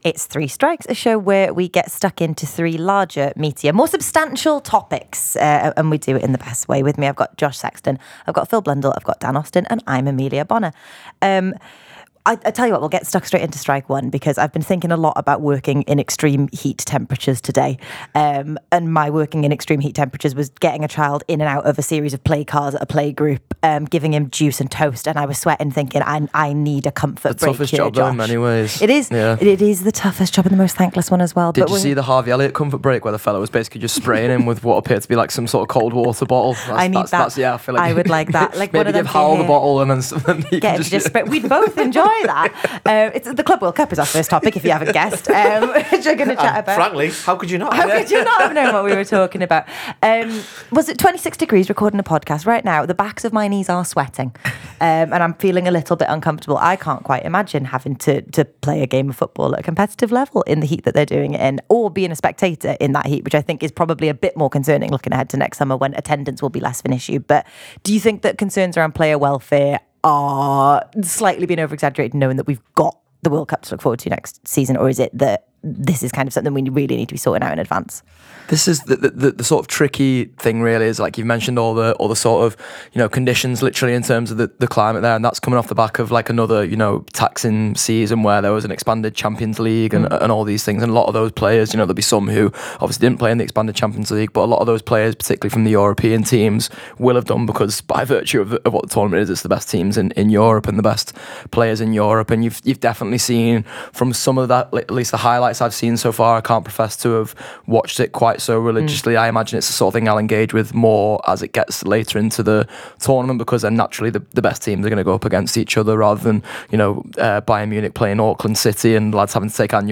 Below is a clip from the show – subscribe for more on the popular topics of the week…